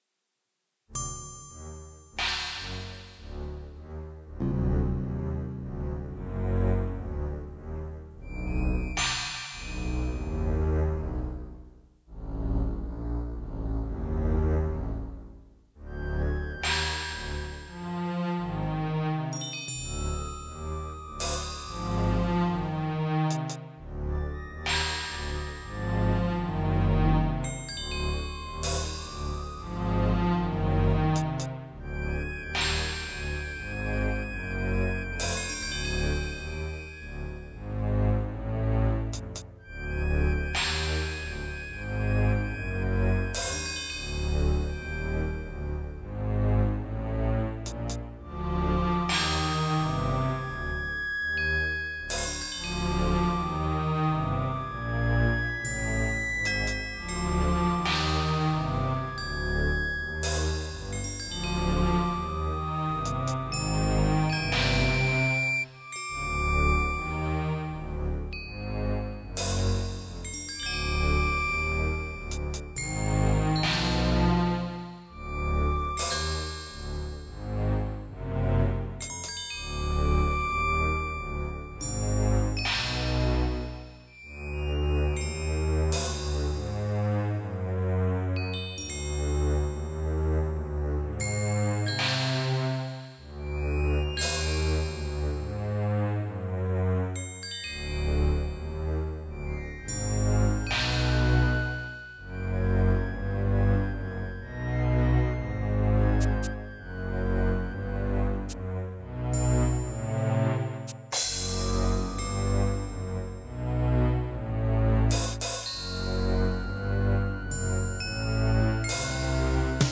This is the background music for a certain area of the game.